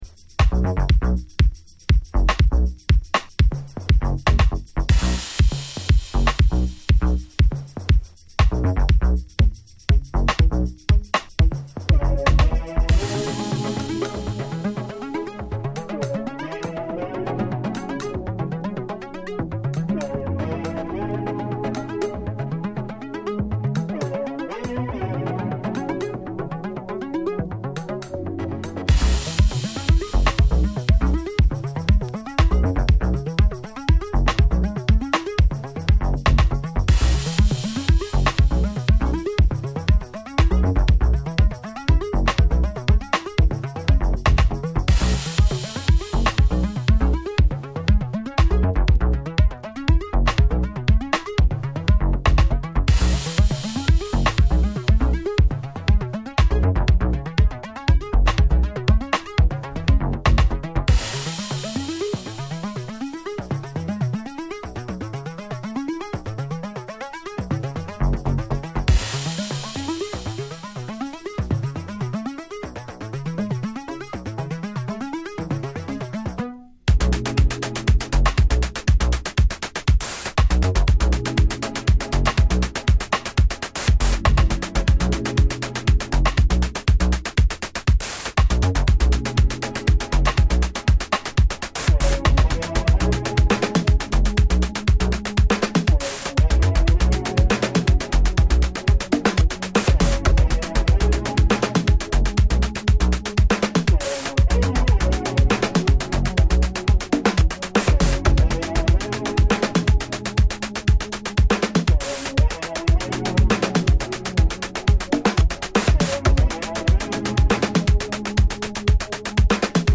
steady 4/4 tracks